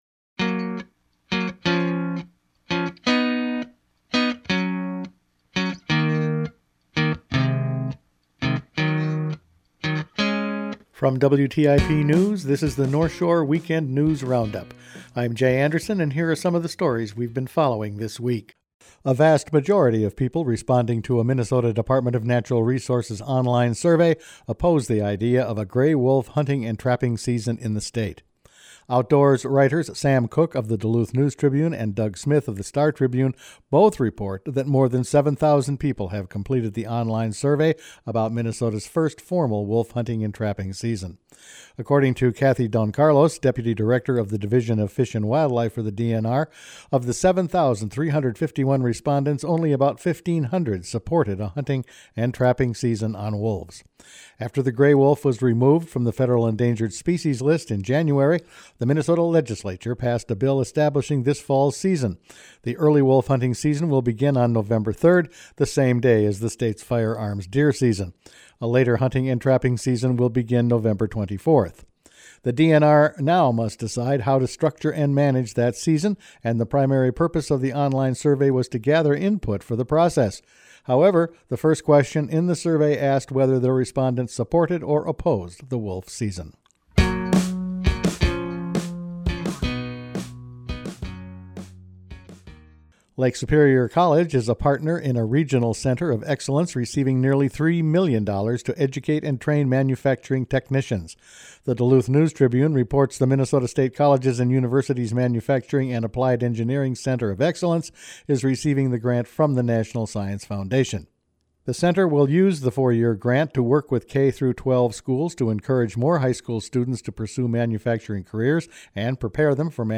Each weekend WTIP news produces a round up of the news stories they’ve been following this week. Wolf season isn’t popular with the public, Lake Superior College gets a big grant, FEMA was in the area looking at flood damage, Lake Superior flood pollution is a worry, a special session on flood damage is probable and the small plane search is called off…all in this week’s news.